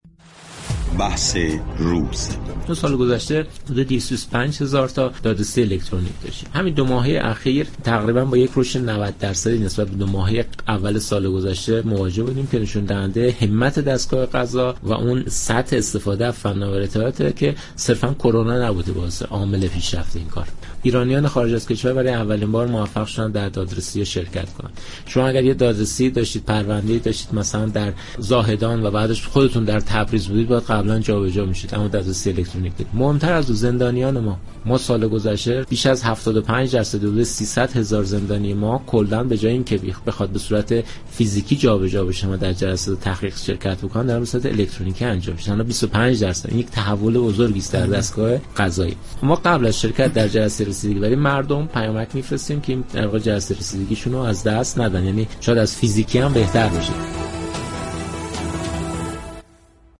سند تحول دستگاه قضا و توسعه خدمات هوشمند در این قوه، موضوعی است كه برنامه زنده «بحث روز» رادیو ایران با حضور مسئولان این حوزه به آن پرداخت.